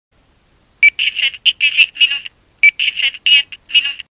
Náramkové hodinky s funkcí hlasového oznámení aktuálního času.